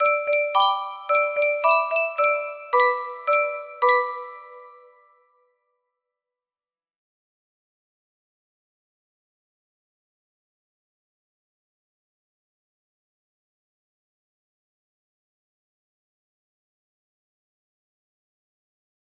Granfatyher clock without high pitch music box melody
Yay! It looks like this melody can be played offline on a 30 note paper strip music box!